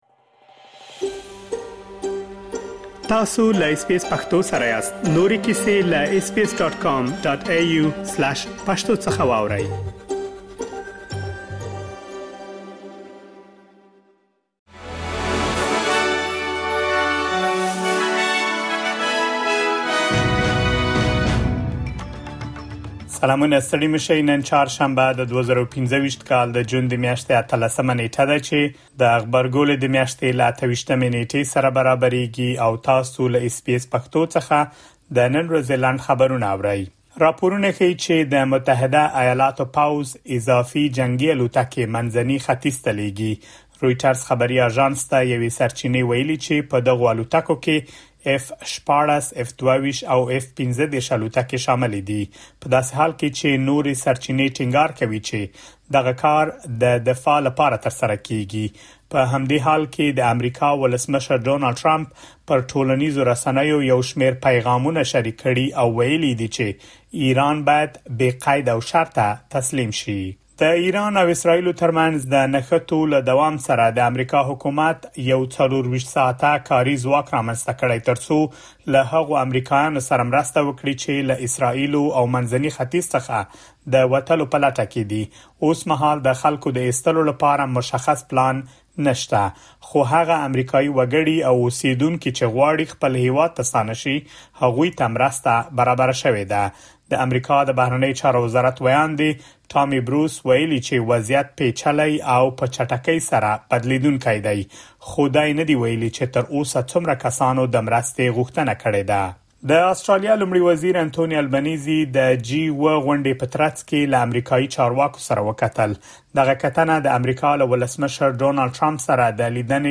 د اس بي اس پښتو د نن ورځې لنډ خبرونه | ۱۸ جون ۲۰۲۵